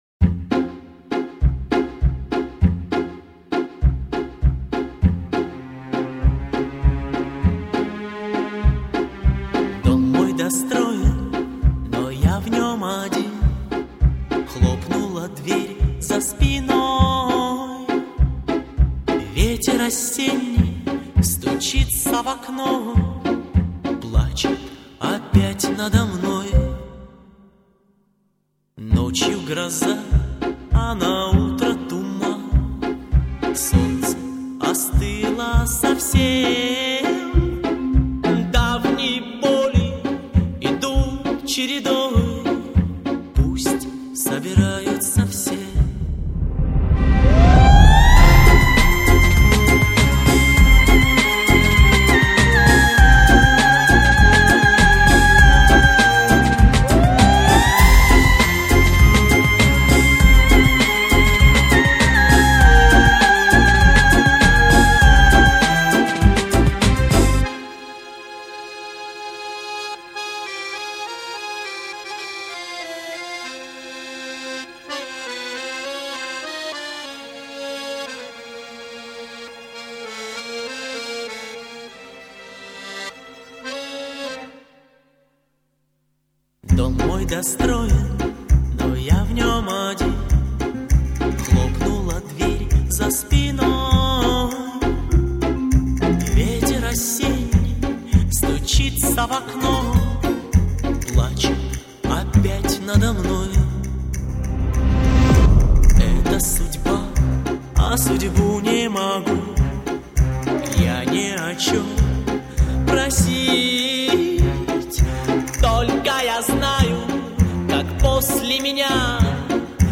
飚的 高音太棒了。